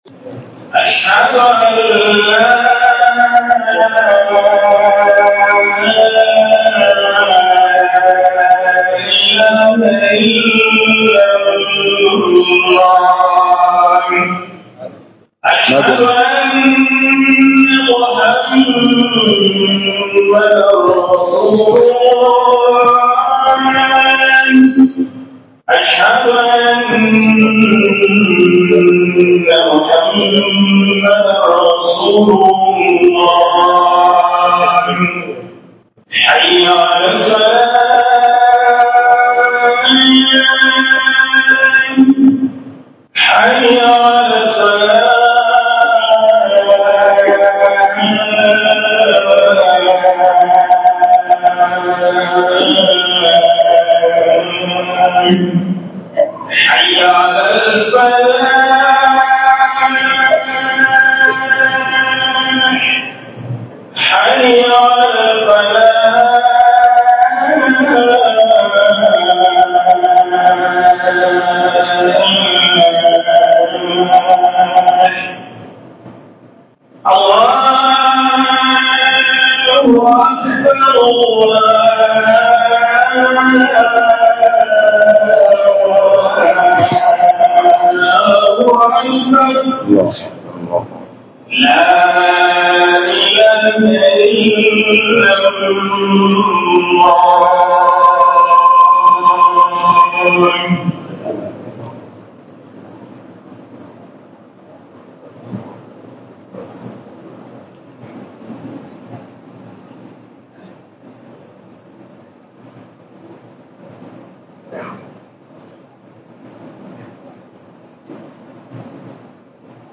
hudubar Juma'a 25 oct._ 2024